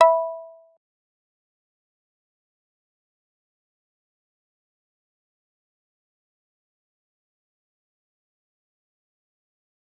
G_Kalimba-E5-pp.wav